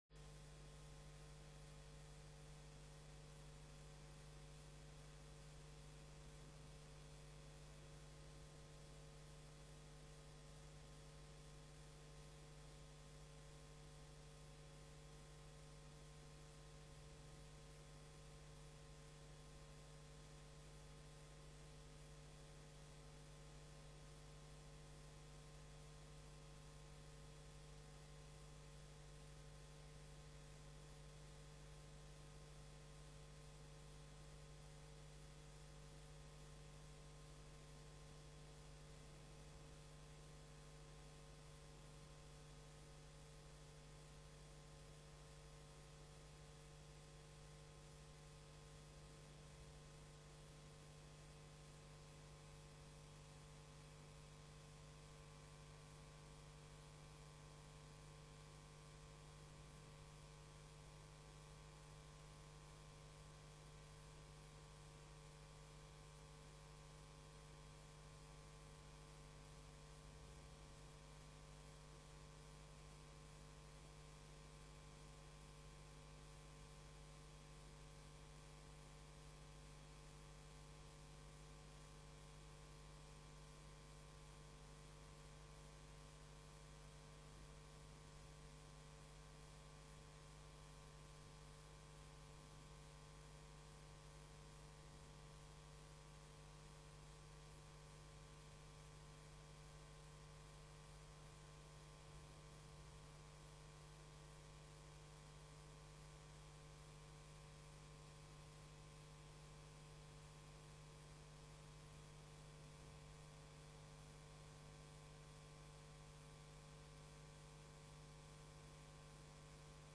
Bijzondere bijeenkomsten 27 april 2012 16:30:00, Gemeente Haarlemmermeer
Locatie: Raadzaal